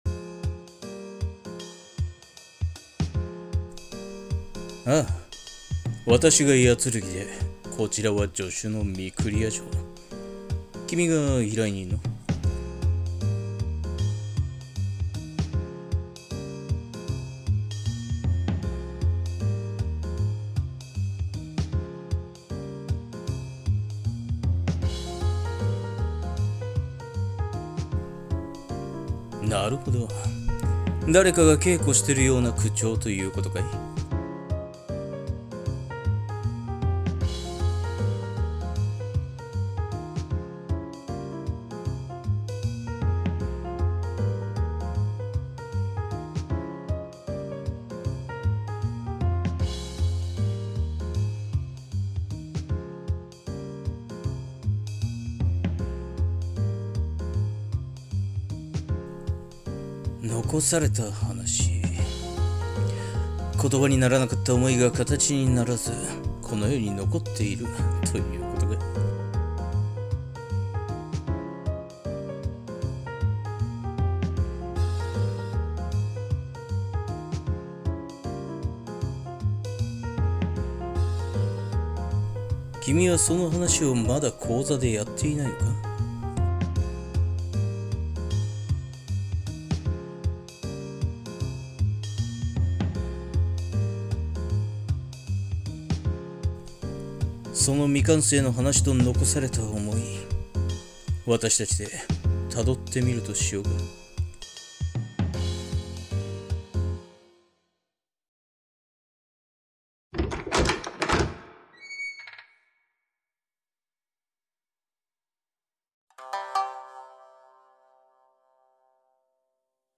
【三人声劇】